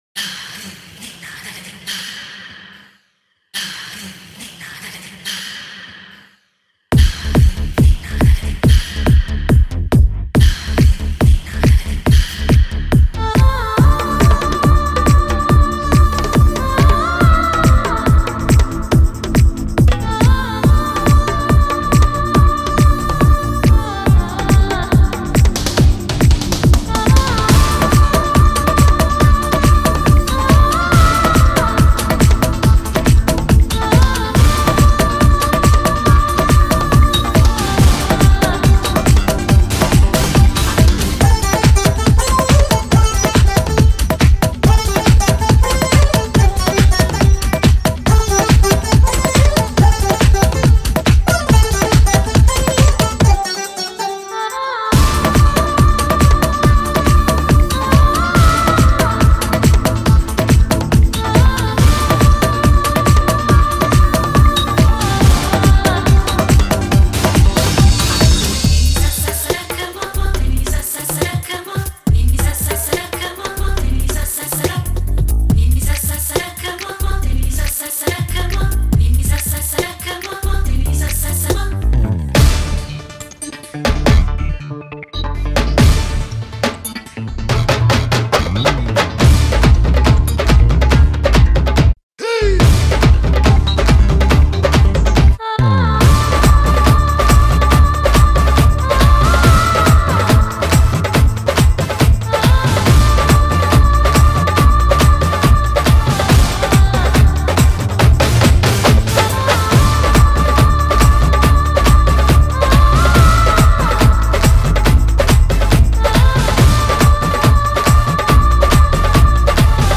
Singer: Instrumental